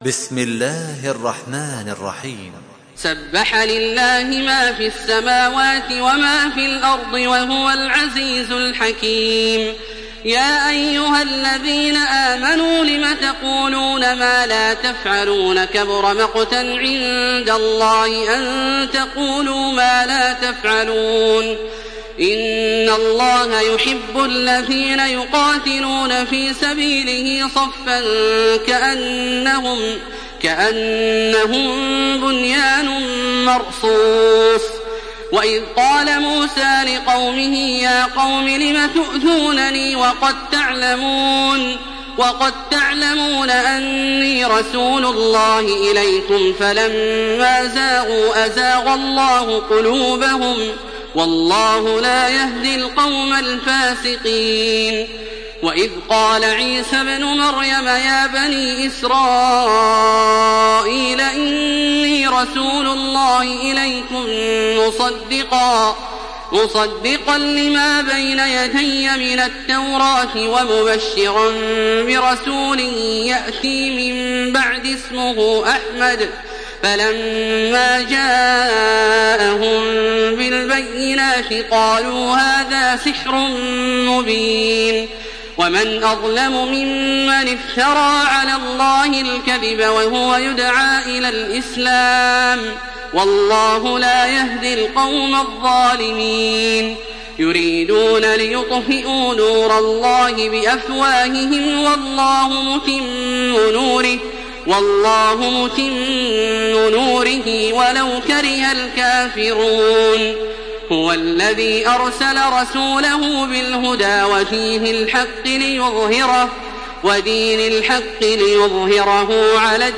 Surah الصف MP3 by تراويح الحرم المكي 1427 in حفص عن عاصم narration.
مرتل